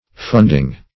Funding \Fund"ing\, a.